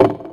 TM88 TMPerc.wav